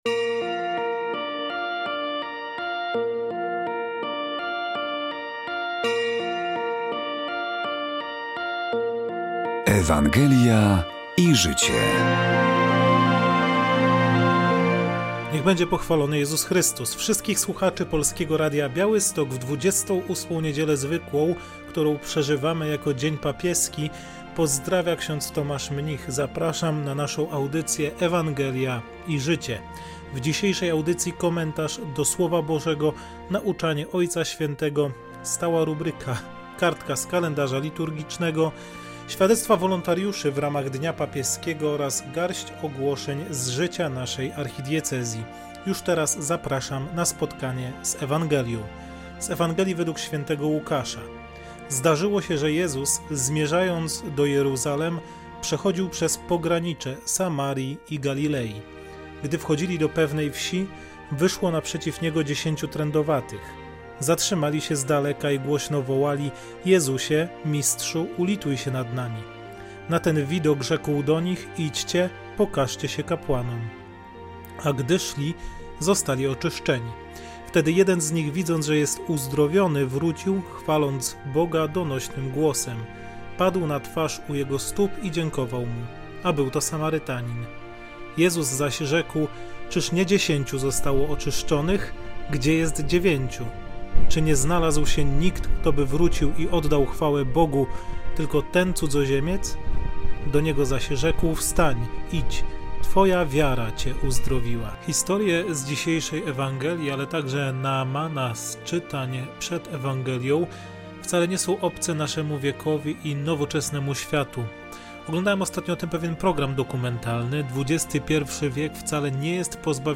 W audycji rozważanie do niedzielnej Ewangelii, nauczanie Ojca Świętego, stała rubryka: kartka z kalendarza liturgicznego, świadectwa wolontariuszy w ramach Dnia Papieskiego oraz garść ogłoszeń z życia naszej Archidiecezji.